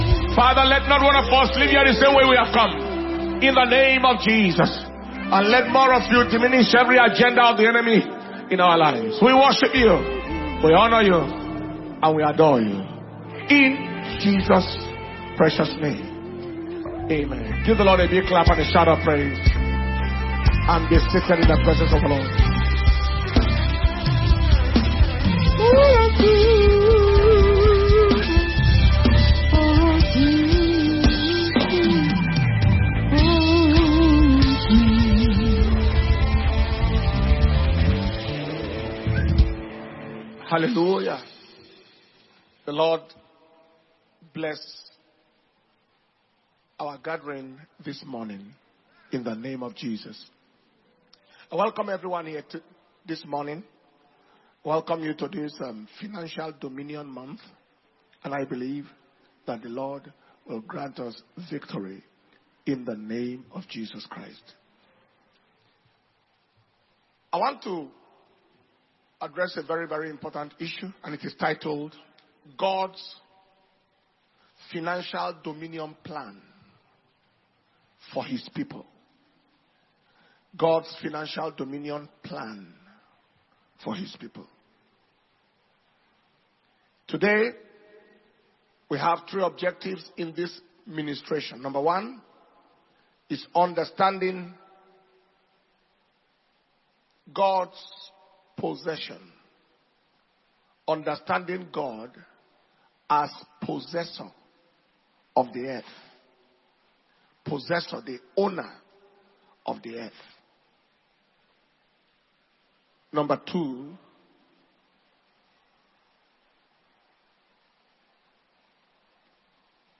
March 2025 Anointing Service - Sunday February 10th 2025